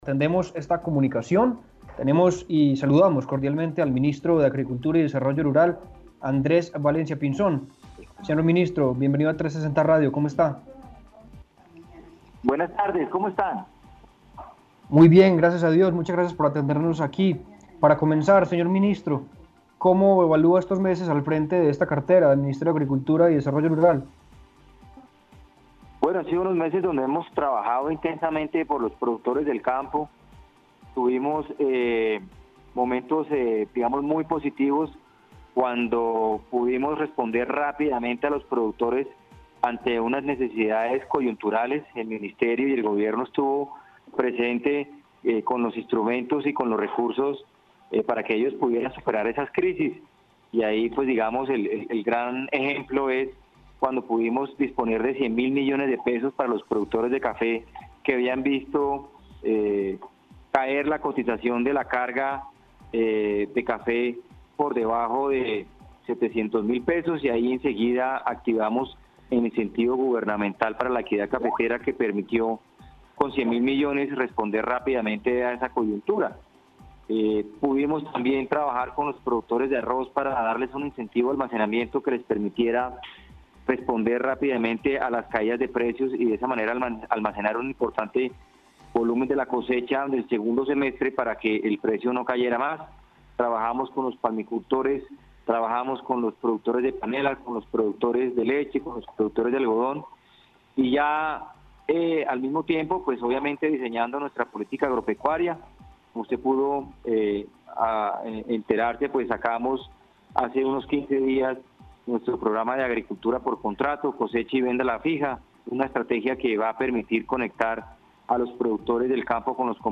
Entrevista completa con el ministro de Agricultura
Entrevista-ministro-de-Agricultura.mp3